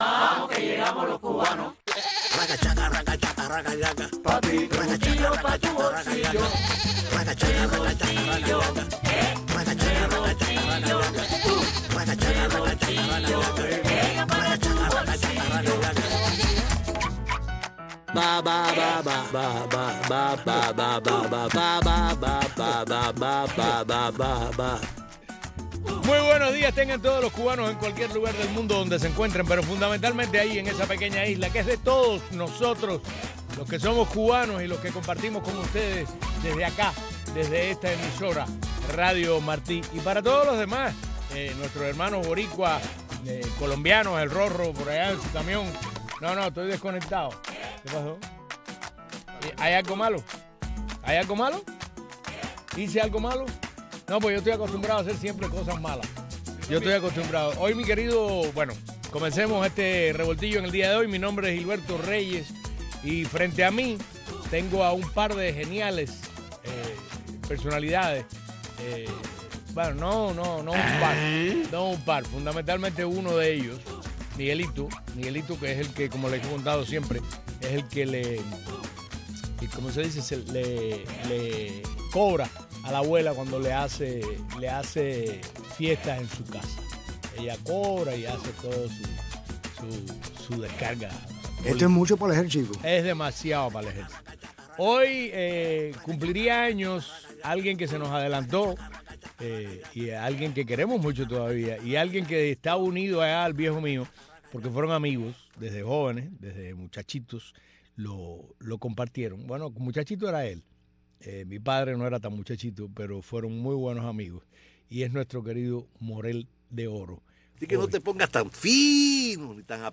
programa matutino